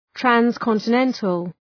Προφορά
{,trænskɒntə’nentəl}